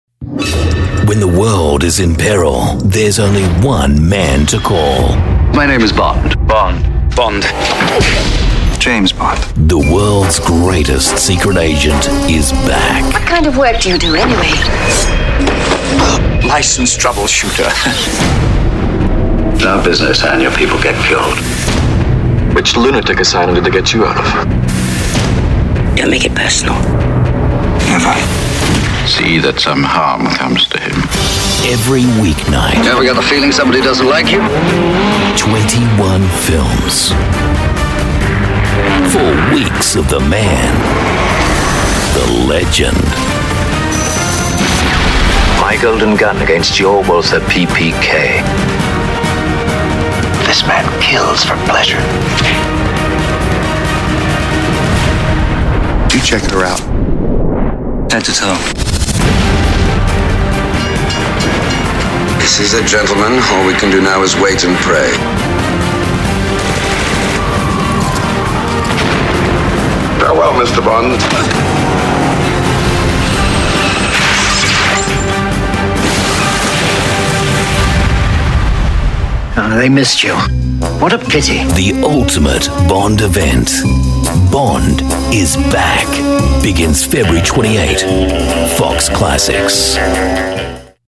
AU ENGLISH